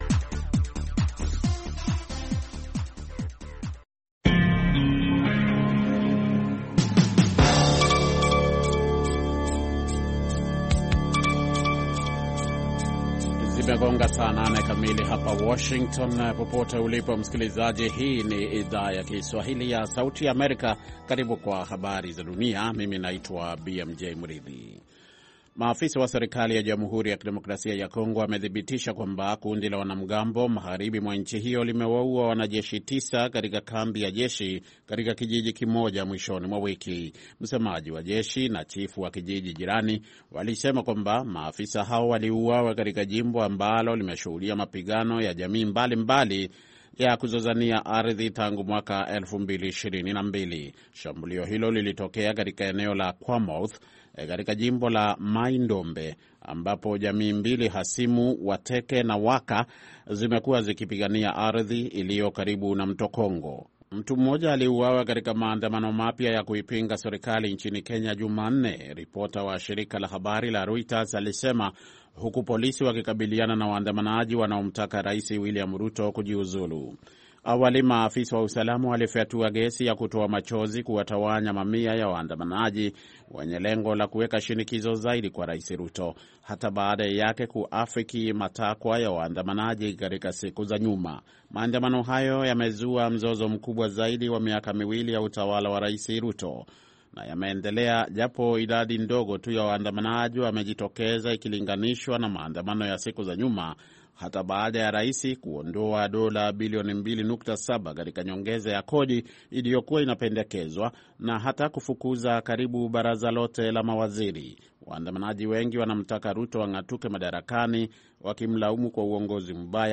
Kwa Undani ni matangazo ya dakika 25 yanayochambua habari kwa undani zaidi na kumpa msikilizaji maelezo ya kina kuliko ilivyo kawaida kuhusu tukio au swala lililojitokeza katika habari.